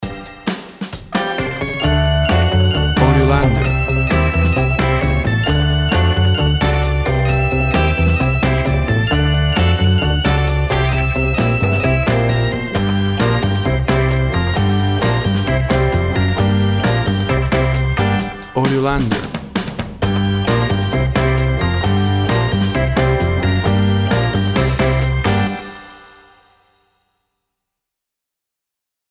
Rock pop of the 60´s vintage.
Tempo (BPM) 135